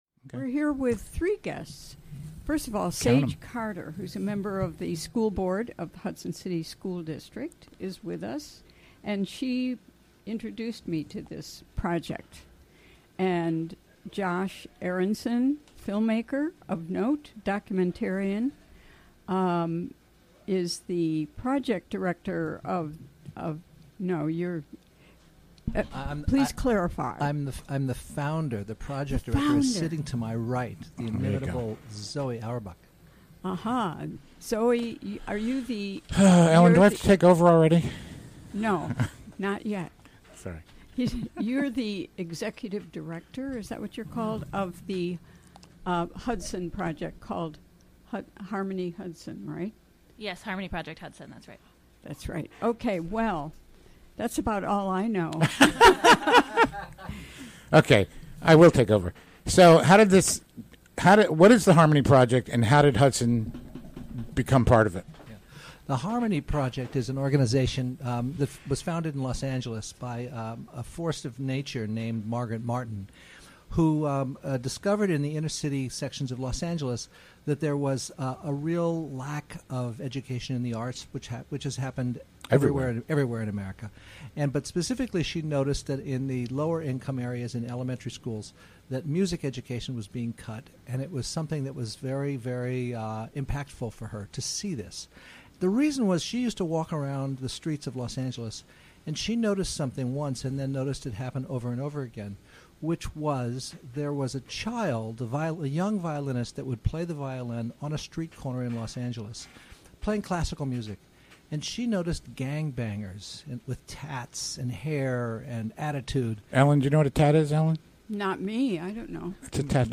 Recorded during the WGXC Afternoon Show Thursday, August 25, 2016.